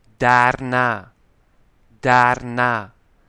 PRONCIATION